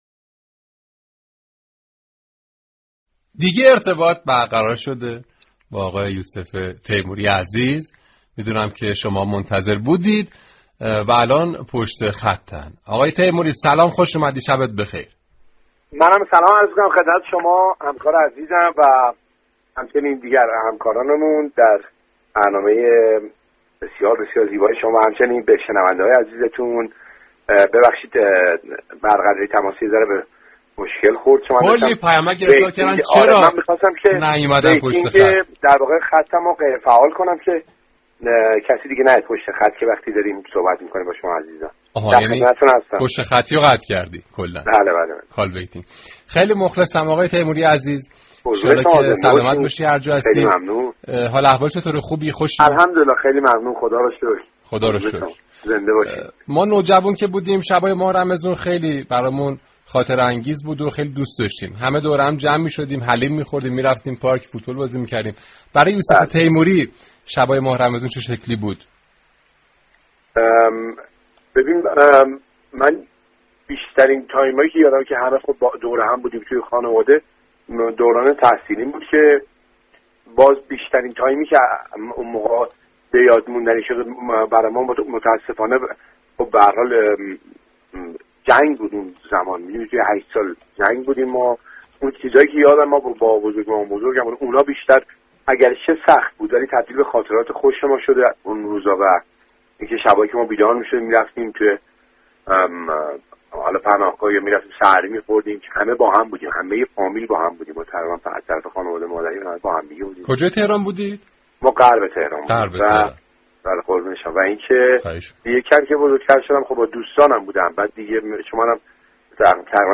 یوسف تیموری در گفتگو با رادیو صبا از خاطرات شیرین خود در ماه مبارك رمضان گفت